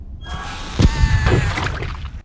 x1_battle_gelin_skill_01.wav